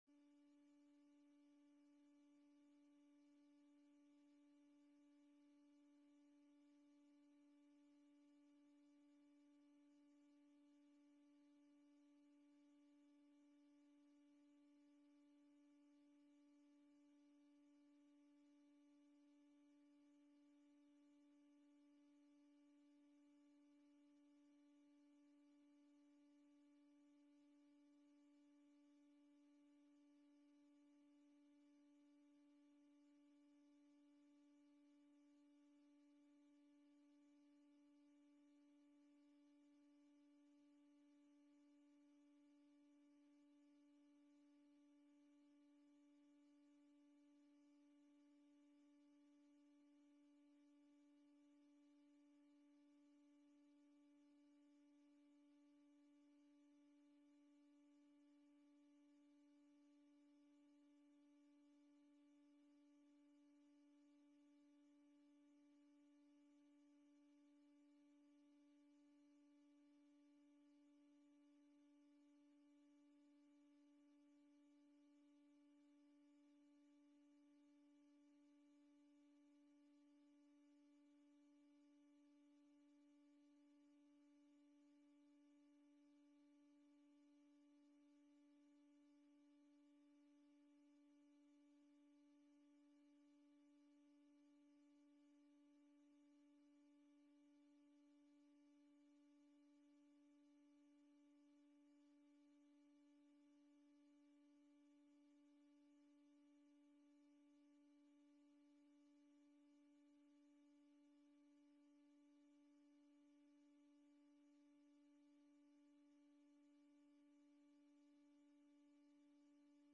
Online, te volgen via livestreaming